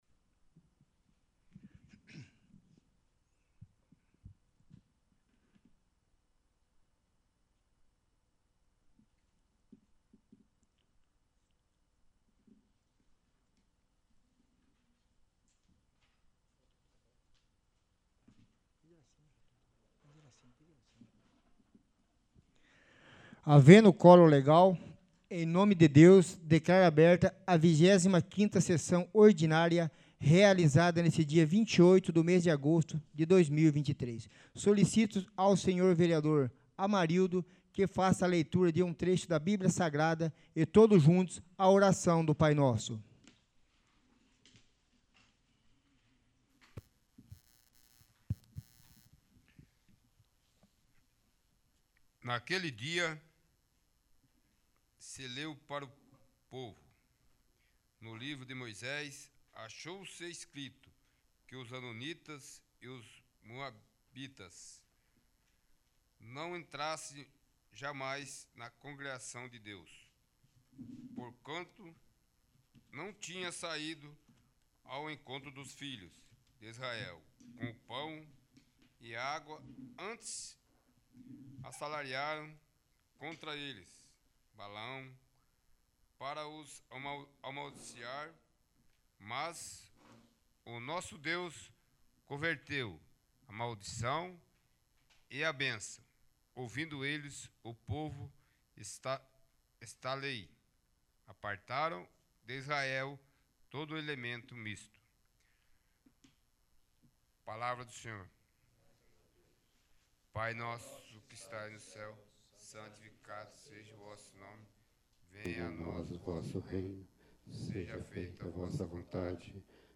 25º. Sessão Ordinária